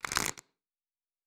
Cards Shuffle 2_10.wav